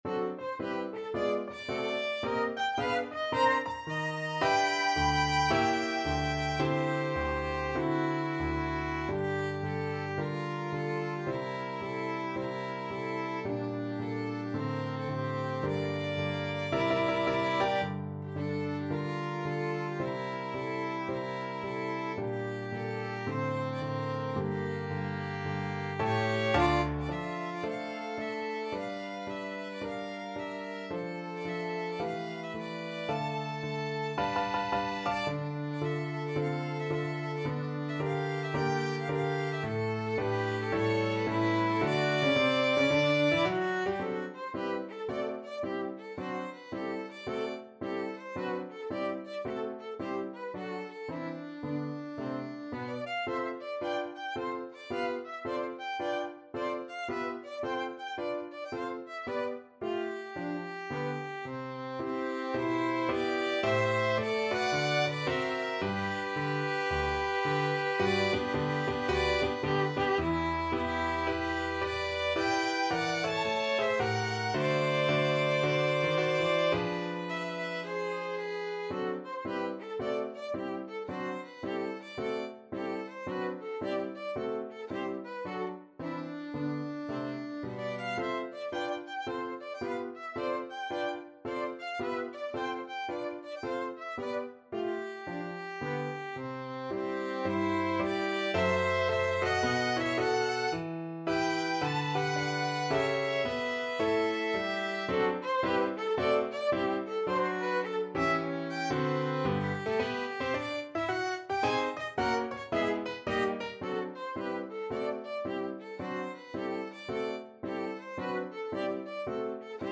Violin 1Violin 2
4/4 (View more 4/4 Music)
Moderato =110 swung
Violin Duet  (View more Intermediate Violin Duet Music)